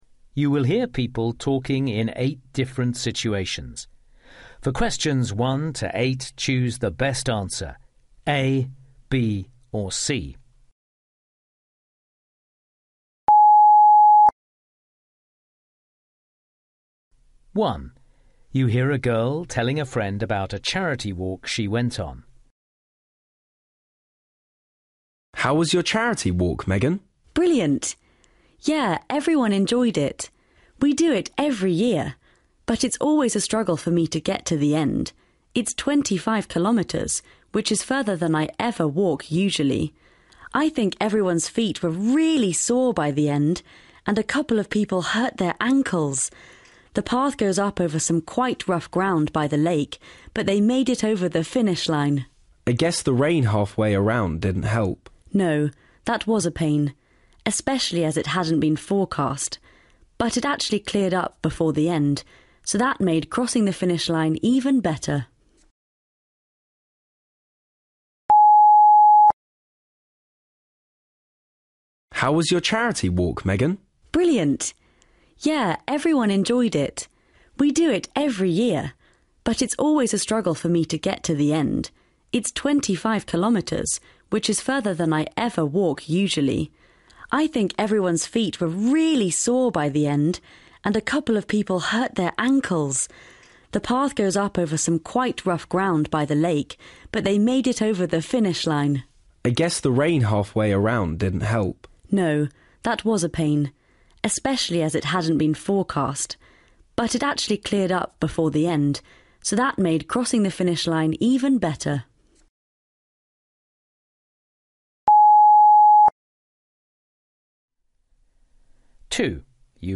Listening 1 You will hear people talking in eight different situations.